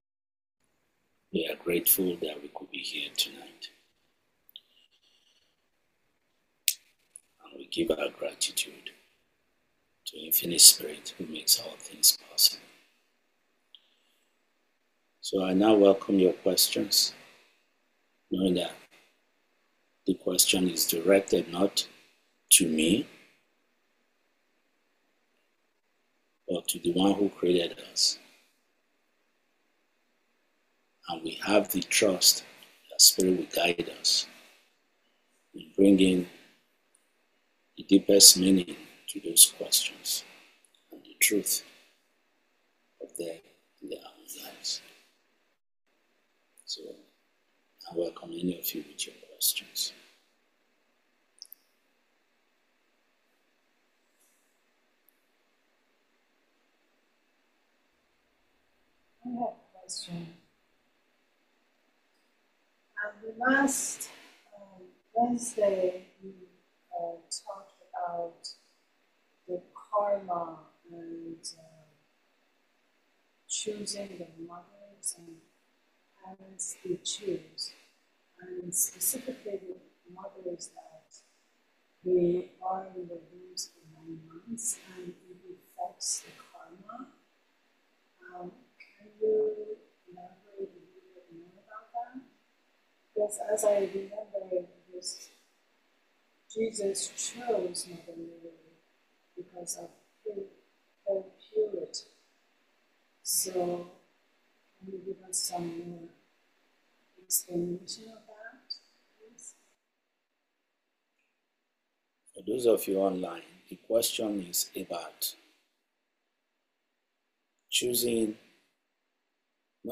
December 2025 Satsang